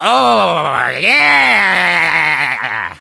Wario gets shocked in Super Mario Strikers and sounds like he is enjoying it.
Wario_(Electrocution_3)_-_Super_Mario_Strikers.oga